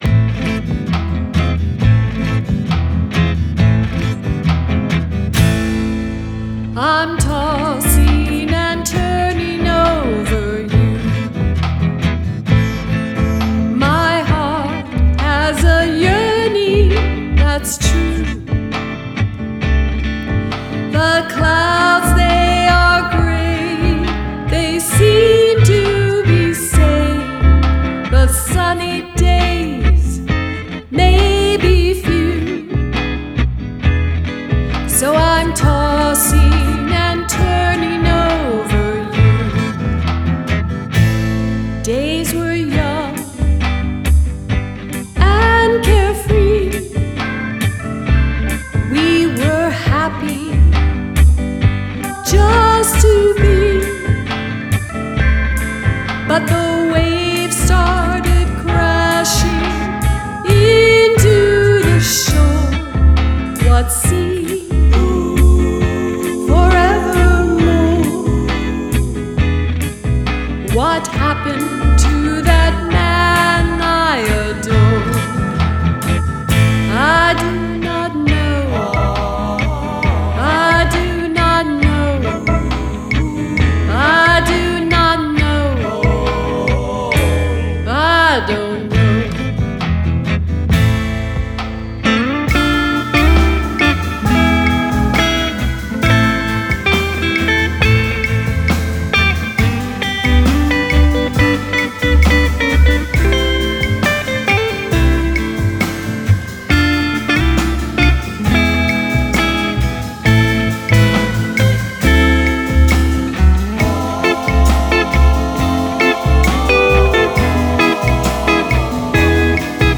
Latin-inspired music